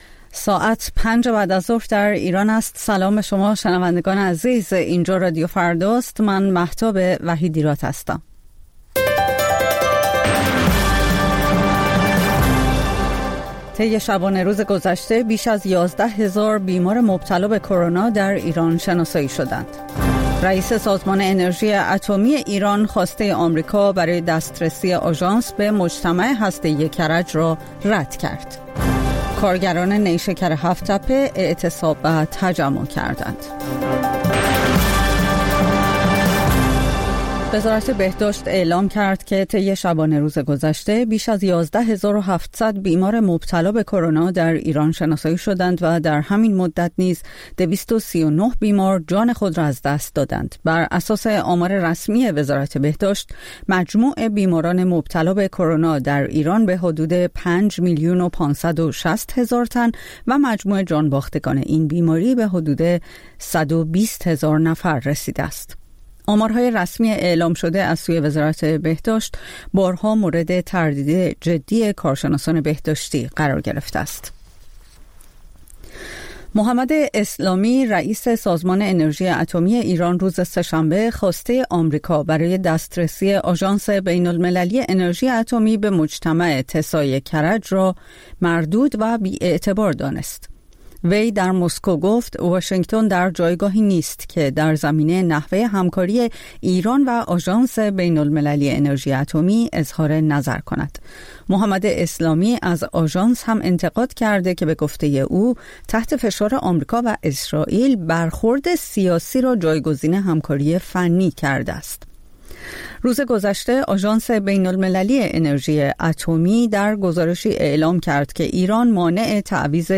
خبرها و گزارش‌ها ۱۷:۰۰